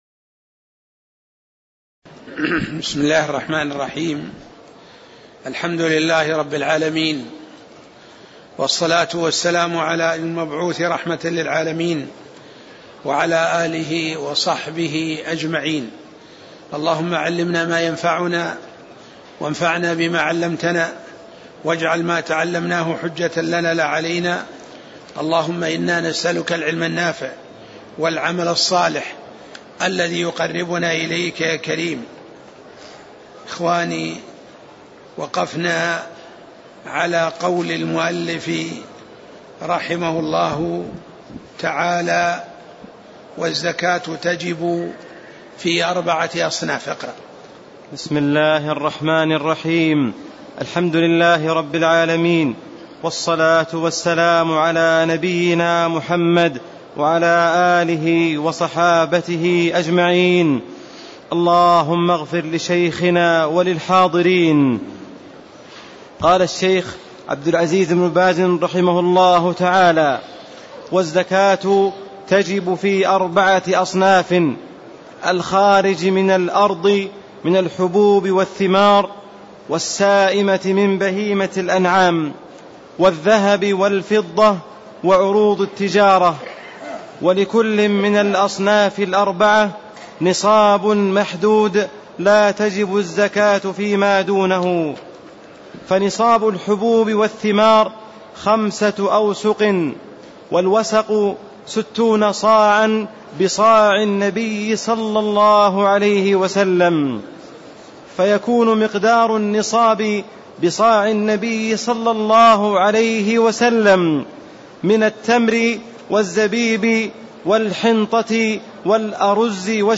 تاريخ النشر ٢٠ شعبان ١٤٣٦ هـ المكان: المسجد النبوي الشيخ